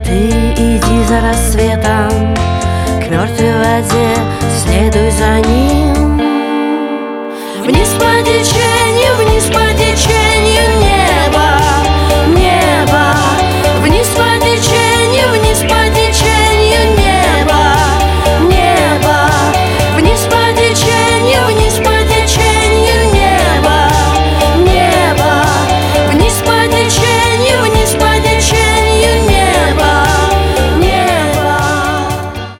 • Качество: 320, Stereo
рок